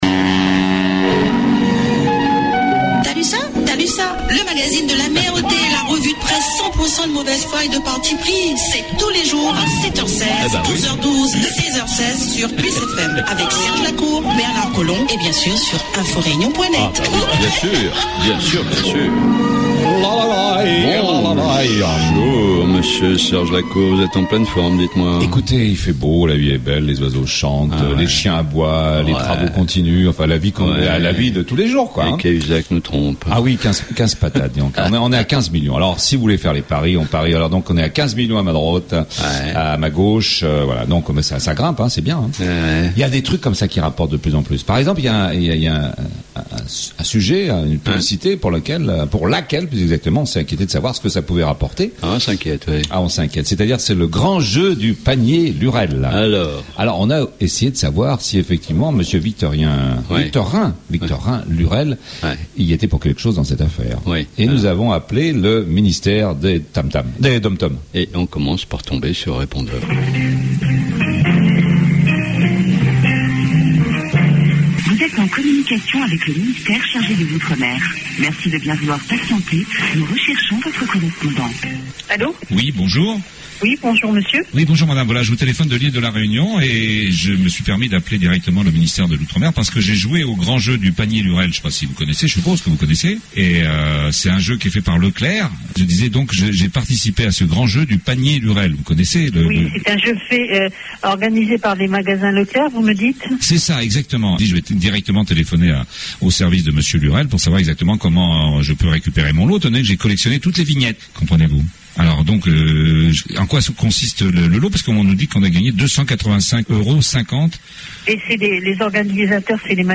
La Revue de Presse politiquement incorrecte la mieux informée, la plus décalée, la plus drôle, la moins sérieuse et surtout la plus écoutée sur PLUS FM 100.6 sur le Nord de l'ile et 90.4 dans l'ouest...